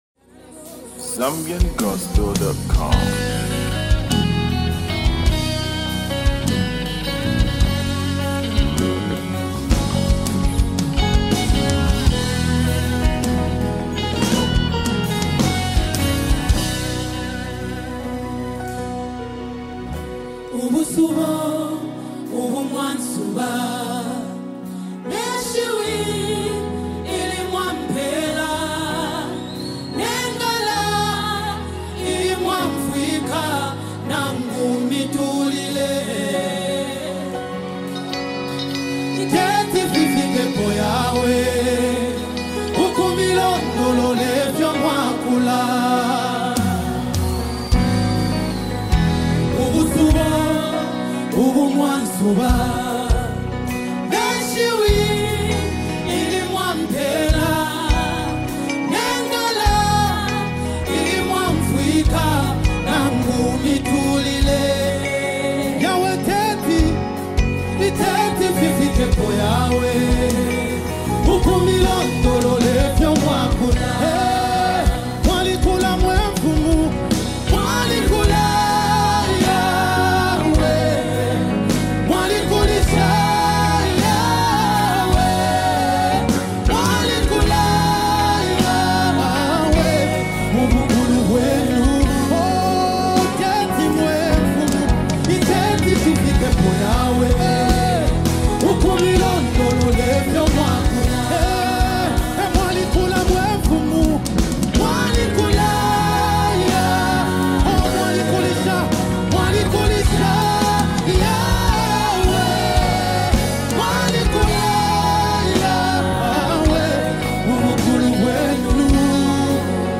Gospel Music
uplifting gospel music